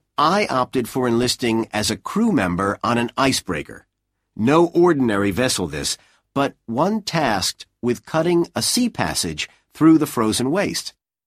Now, let’s state a general rule: broadly speaking, the phoneme /k/ is very frequently dropped when it appears in the middle of the cluster /skt/.